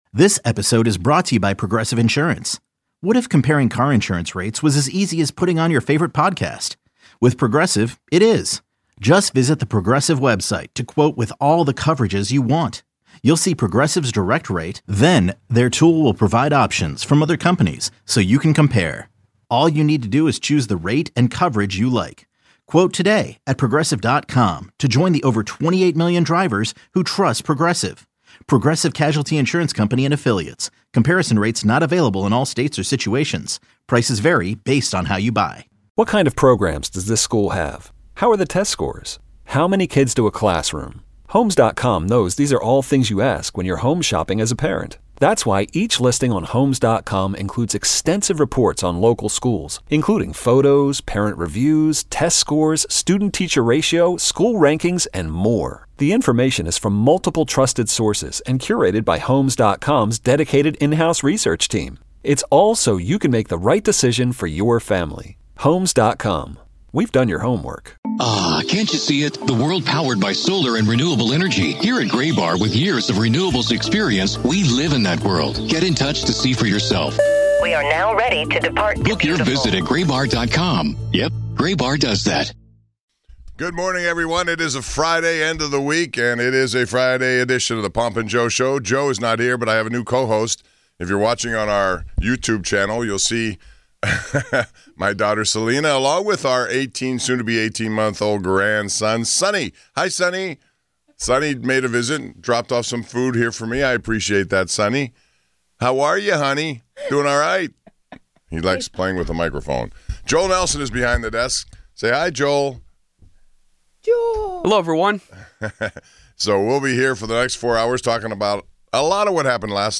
Can the NBA rebound their way to more exciting All-Star festivities like the NHL just put on? Numerous callers share their thoughts.
A few callers chime in on them.